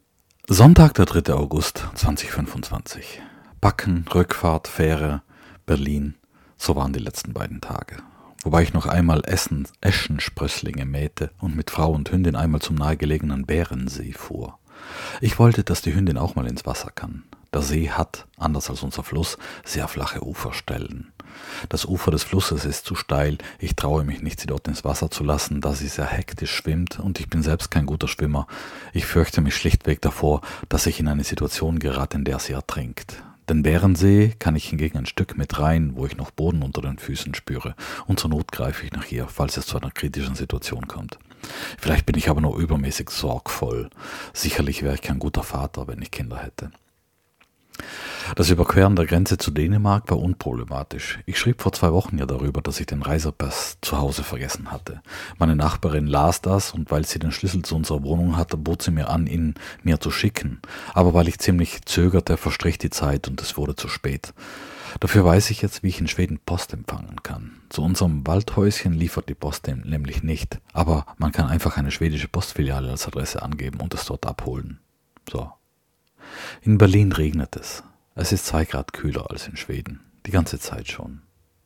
In Berlin regnet es.